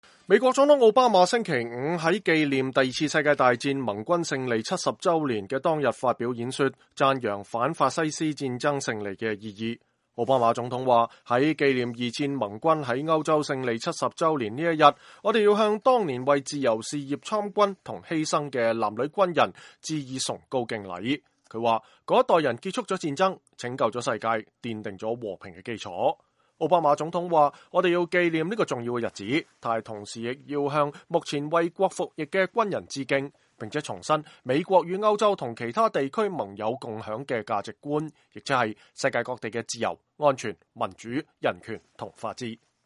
奧巴馬發表講話紀念二戰盟軍勝利70週年